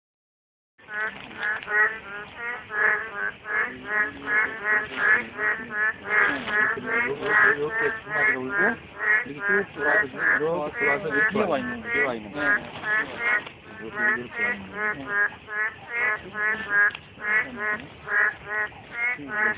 0708맹꽁이소리(추가서식지에서).mp3
오늘은 하루 종일 비가 내렸고 조사를 하면서도 비가 내려,
제내지 수로에서의 맹꽁이가 “맹~”, 제외지에서 맹꽁이가  “꽁~” 하며
거리가 있는데도 서로의 울음소리를 인식하며 울고 있었습니다.
오늘 함께하시지 못한 선생님들을 위해 오늘 들었던 맹꽁이소리 파일을 함께 올려봅니다.